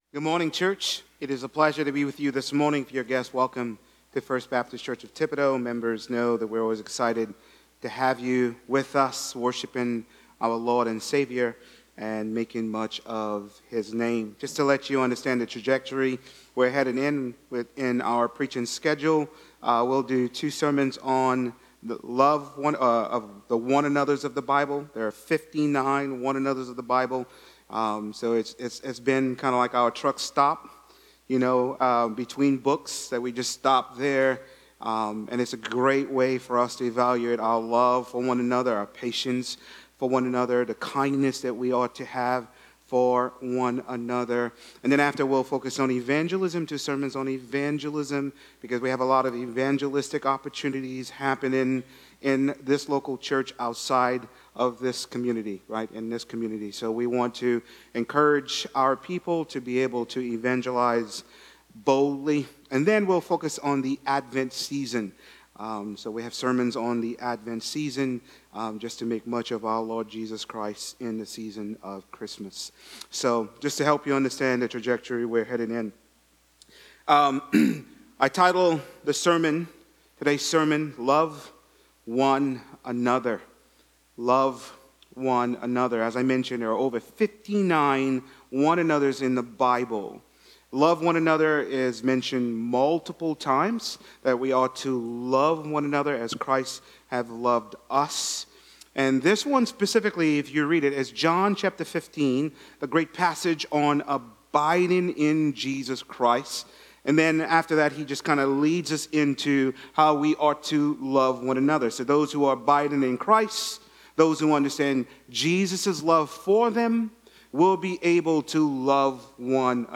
Sermons – FBC Thibodaux